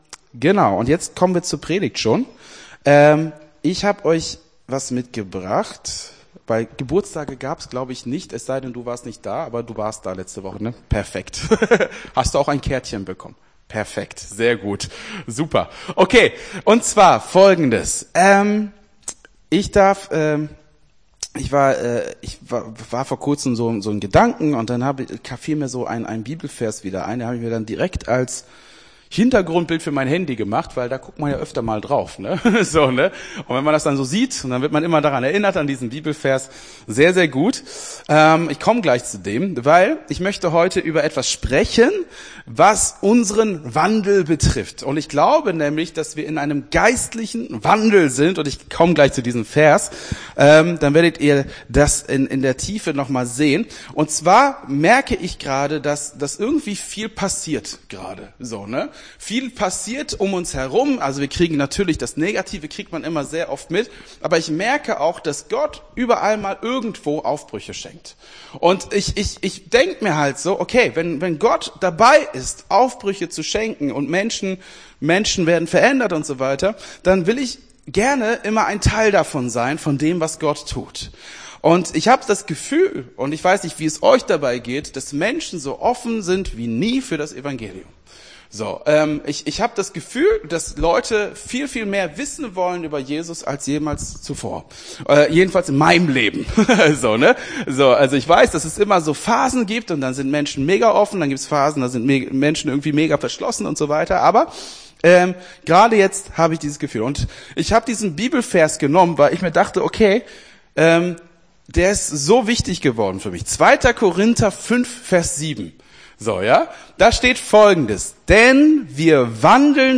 Gottesdienst 25.02.24 - FCG Hagen